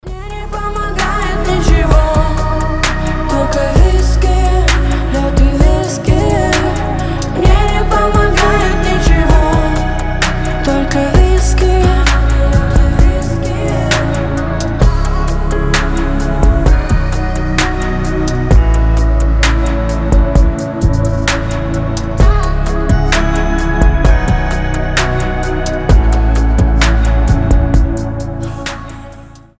• Качество: 320, Stereo
грустные